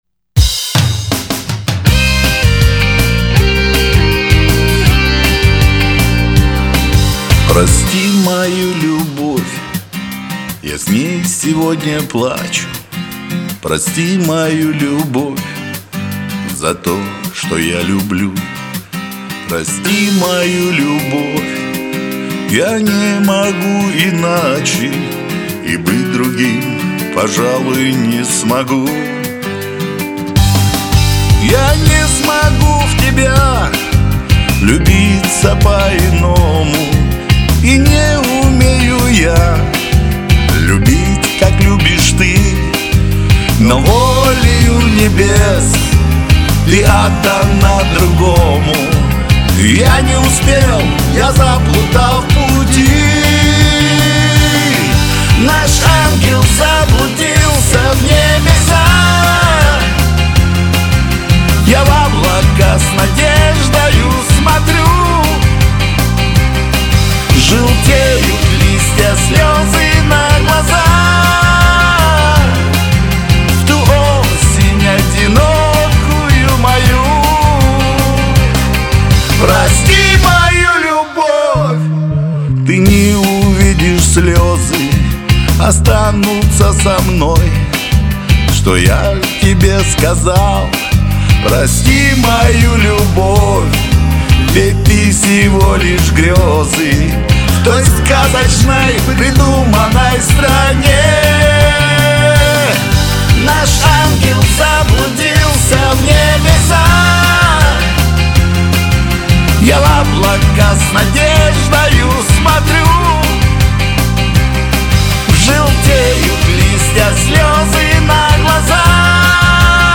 Разные манеры одного исполнителя.
Оба Молодца, голоса и спето красиво!